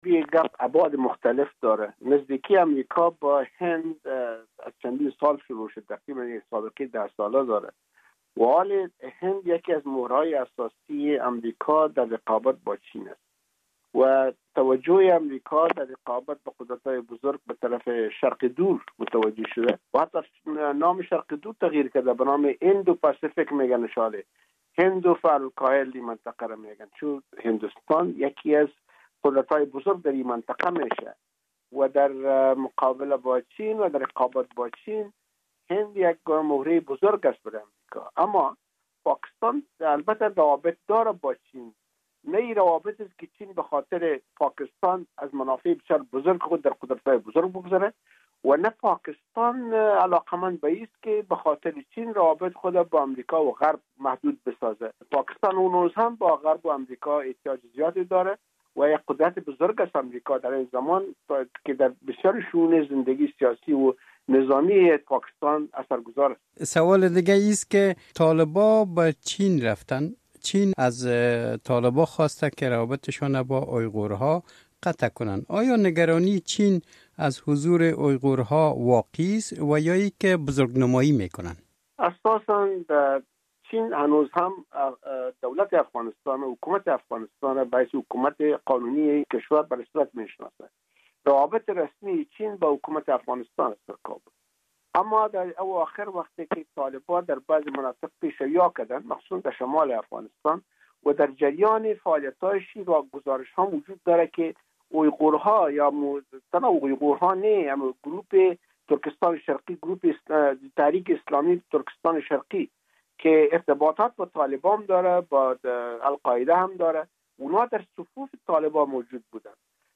گفتگو با علی احمد جلالی